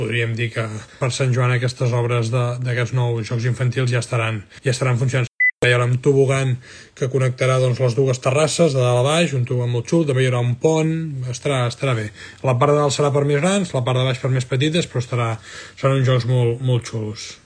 L’Ajuntament ha facilitat imatges del resultat del procés de renovació de l’espai, que estarà enllestit abans de l’estiu, tal com va avançar l’alcalde Marc Buch en un directe a les xarxes socials.